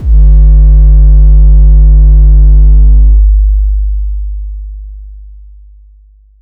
Kick Bass 2.wav